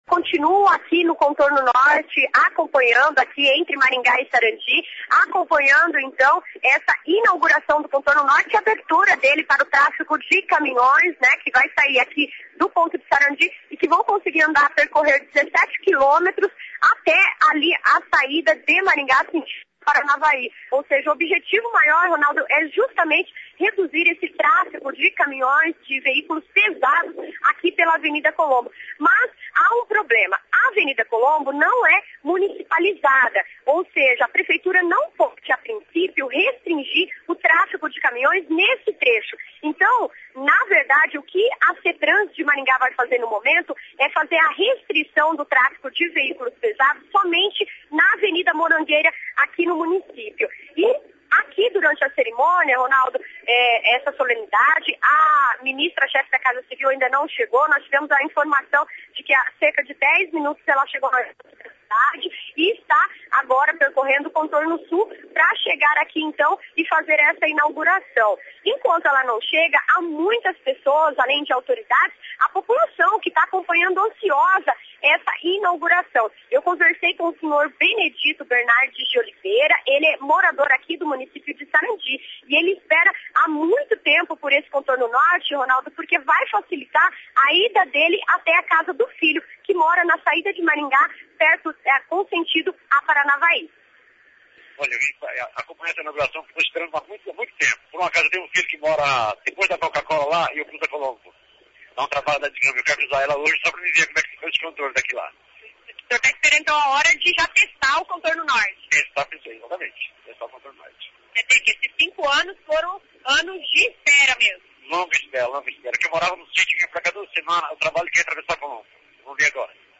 AO VIVO CONTORNO NORTE C.B 10.01.mp3